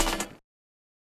Perc [VaporWave TypIcAl].wav